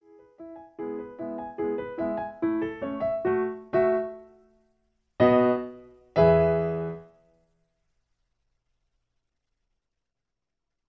In B, E. Piano Accompaniment